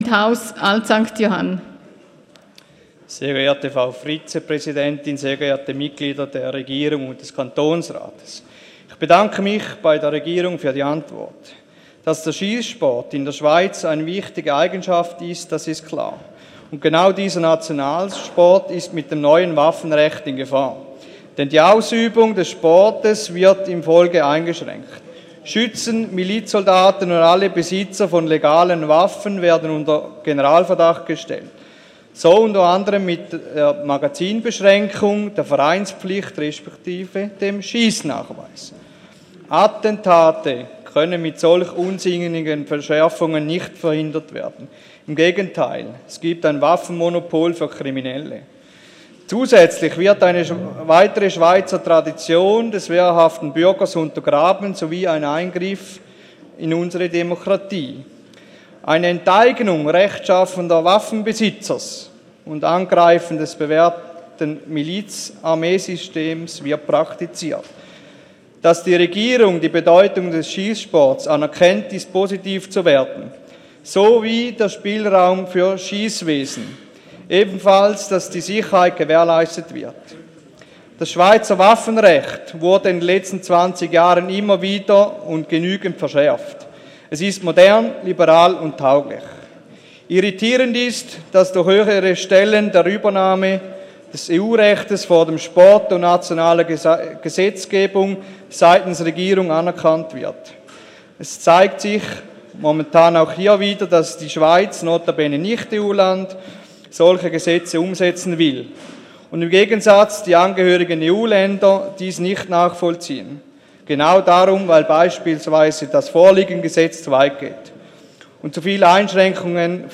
28.11.2017Wortmeldung
Session des Kantonsrates vom 27. und 28. November 2017